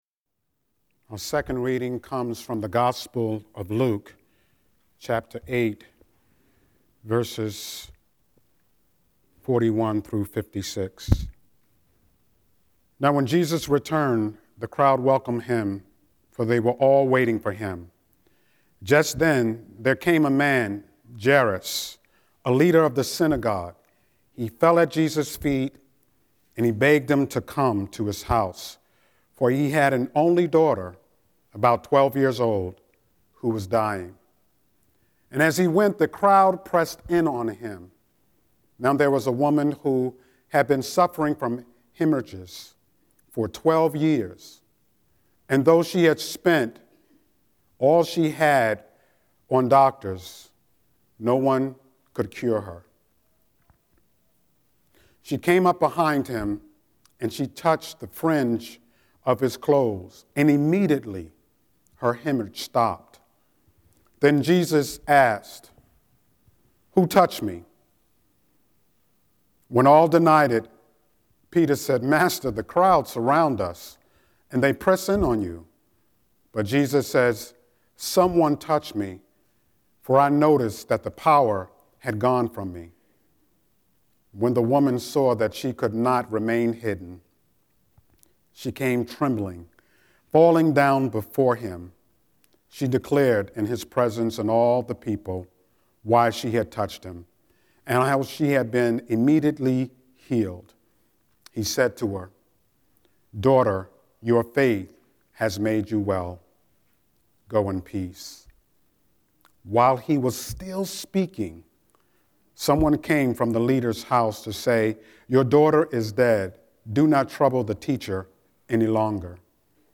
10-11-Scripture-and-Sermon.mp3